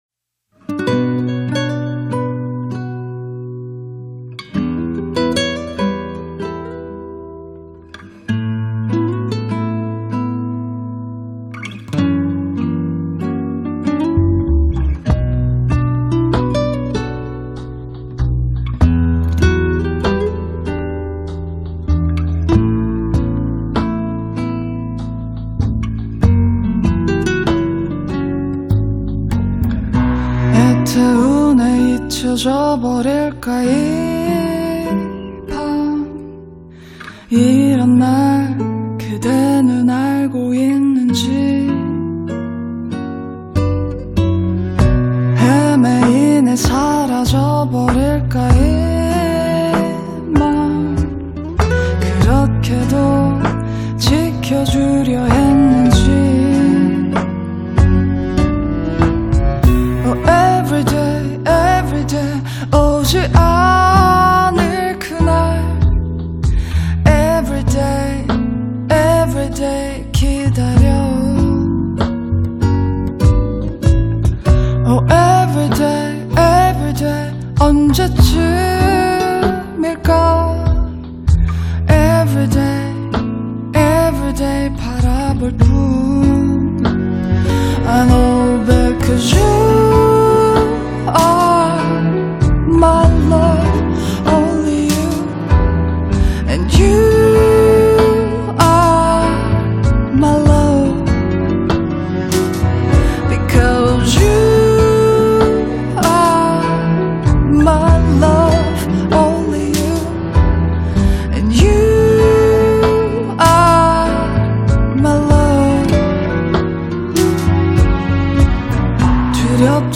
2 guitars, drums, strings
I love how the song peters out on a vamp at the end.